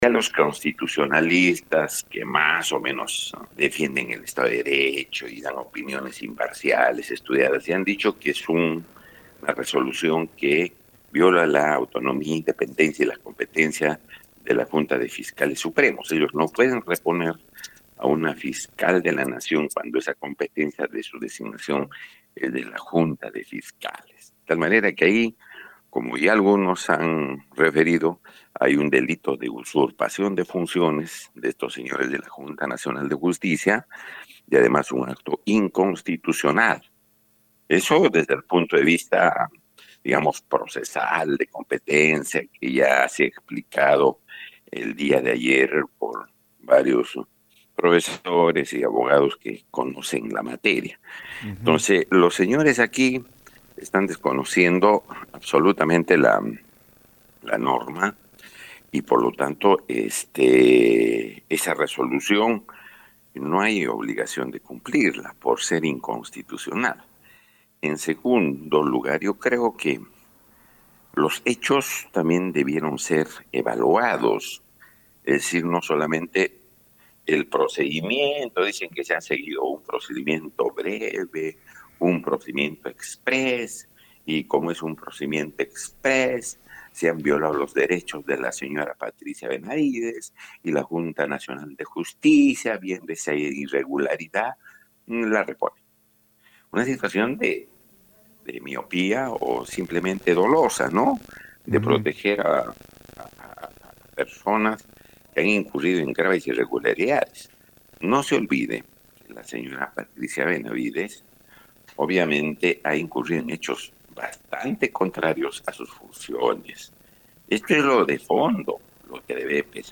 De igual forma, consultado por el tema; el excongresista Yonhy Lescano advirtió que la JNJ está incurriendo en delito de usurpación de funciones y acto inconstitucional esto desde el punto de vista procesal que ha sido explicado por varios abogados que ya conocen la materia.